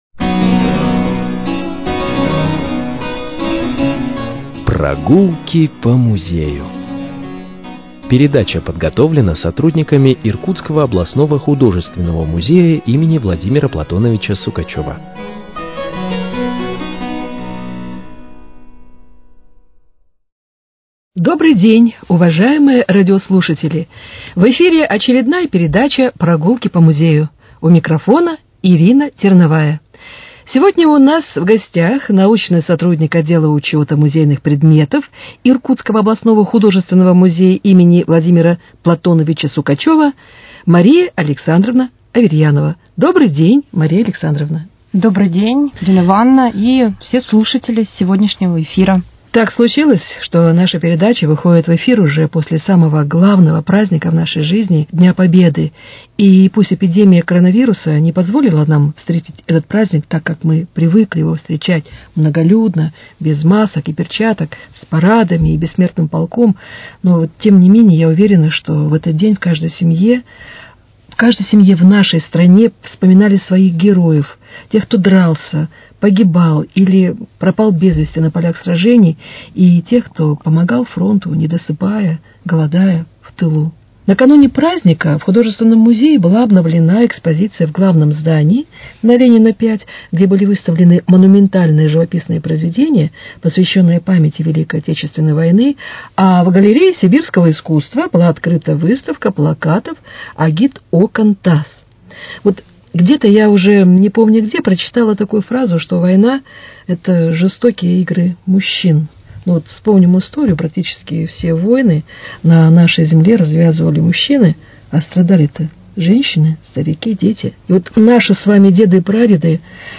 Передача из авторского цикла